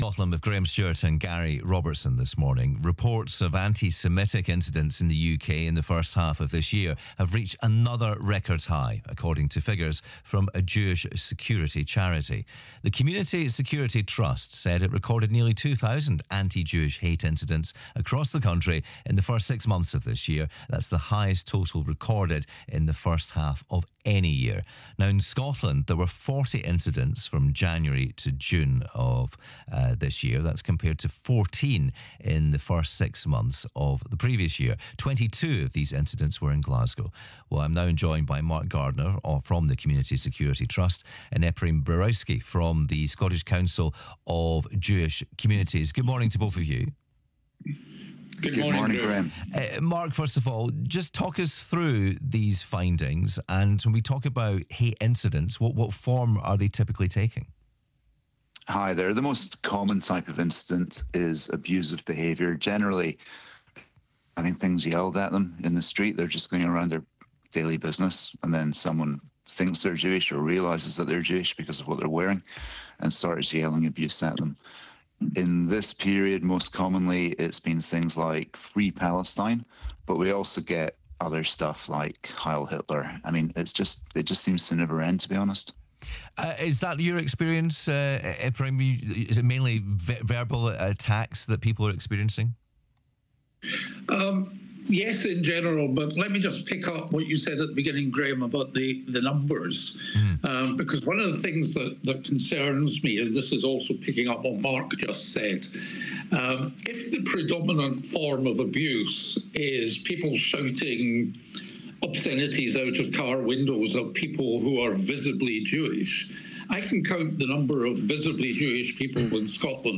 BBC Scotland interview about the latest CST antisemitic incident statistics